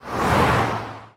car1.ogg